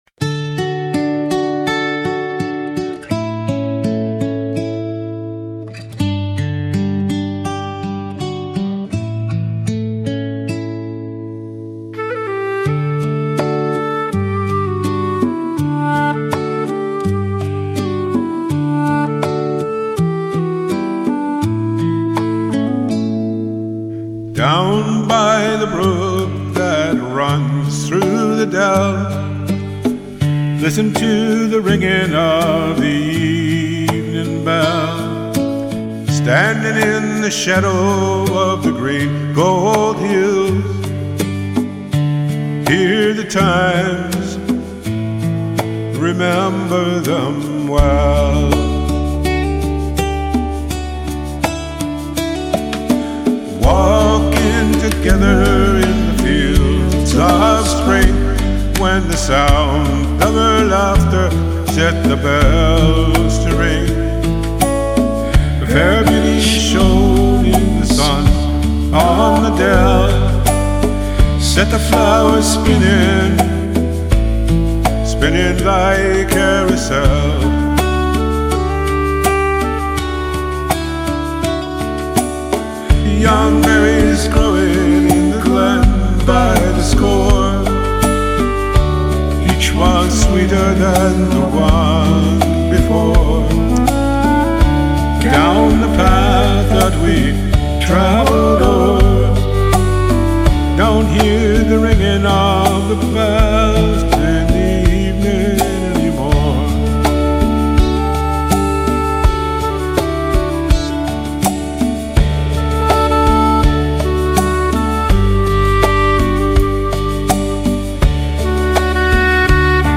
adding a new background track
Here’s my cover.